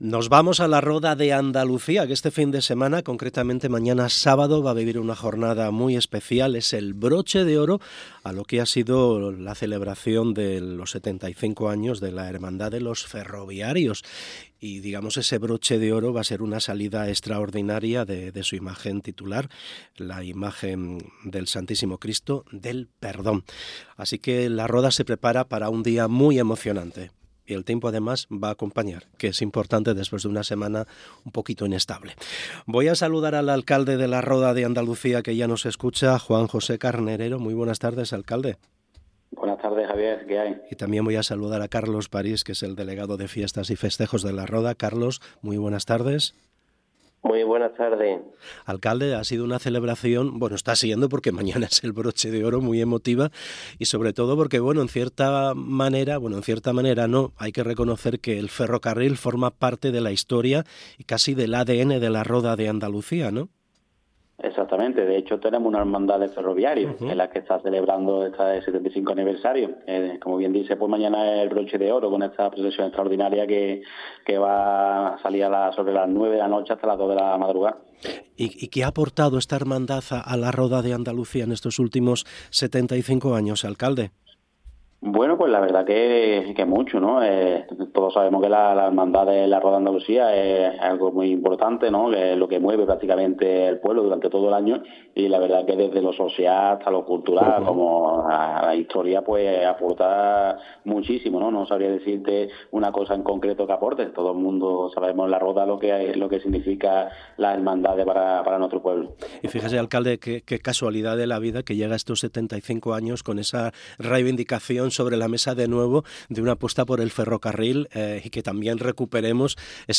Entrevista Juan José Carnerero y Carlos París
Hablamos con Juan José Carnerero, alcalde de La Roda, y Carlos París, concejal delegado de fiestas y festejos